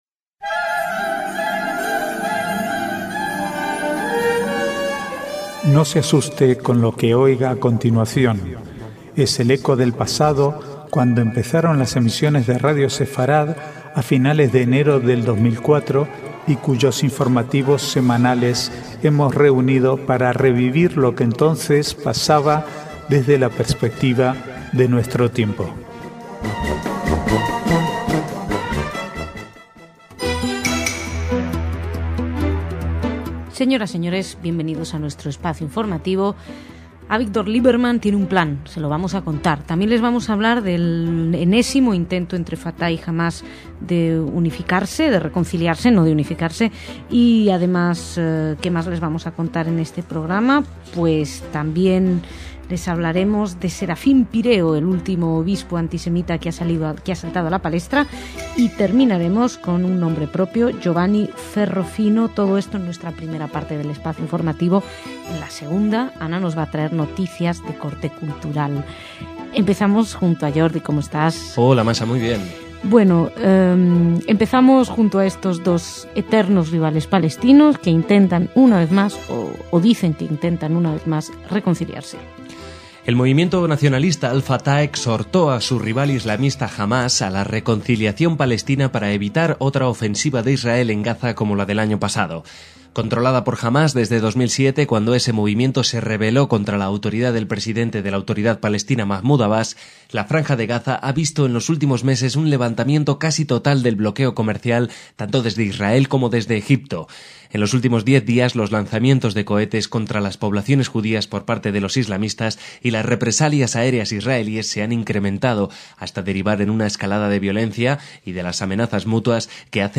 Archivo de noticias del 28 al 31/12/2010